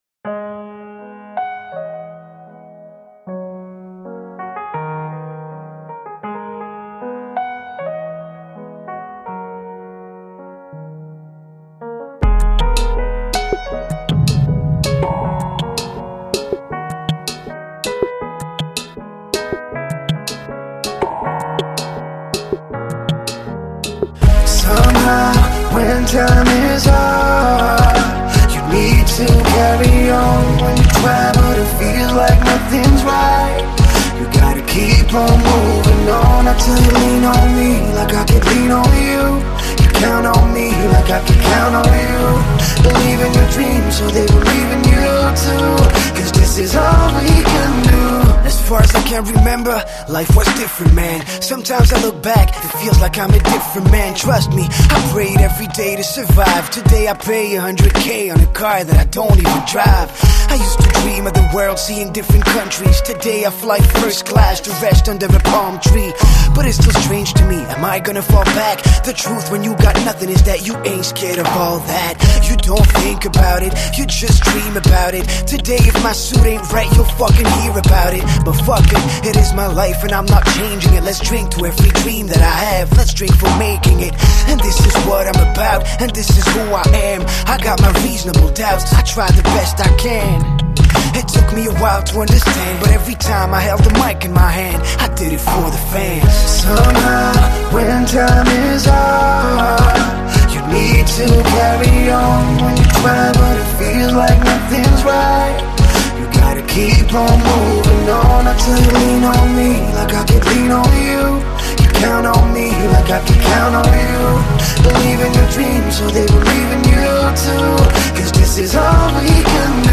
Жанр: Rap & Hip Hop